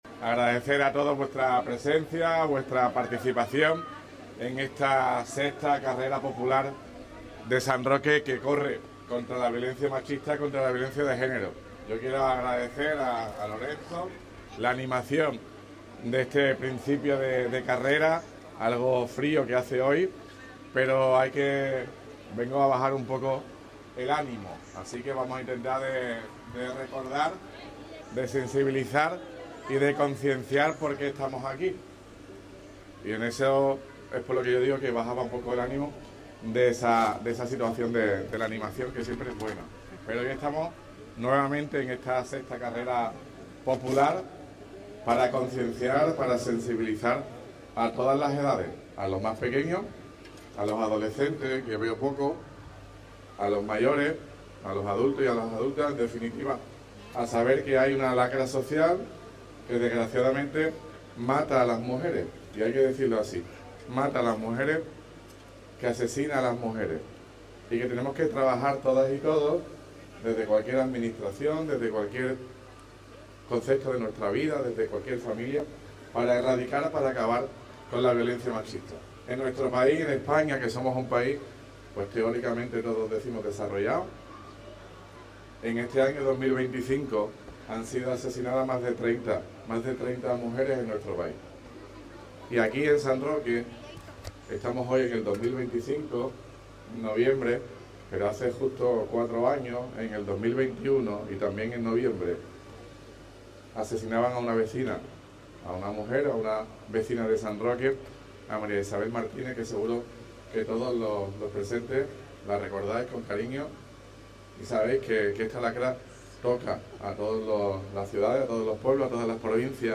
TOTAL ALCALDE CARRERA 25 N.mp3